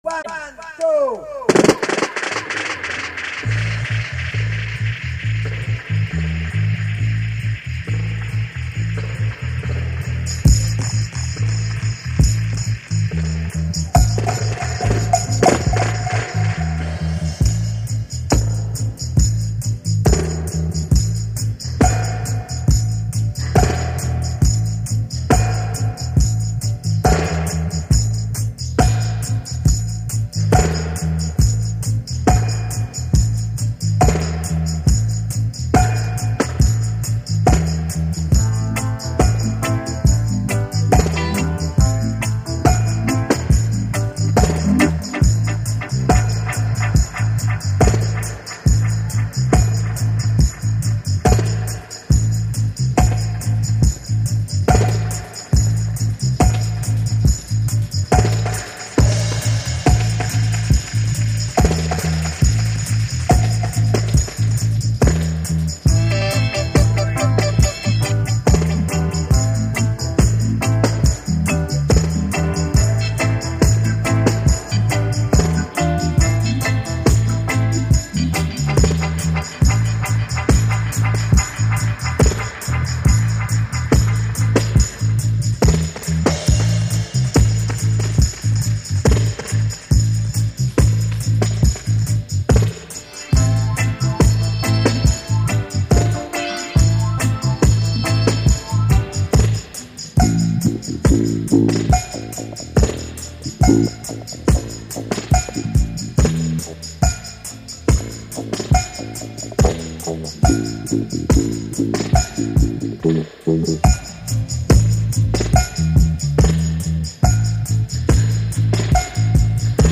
Reggae/Dub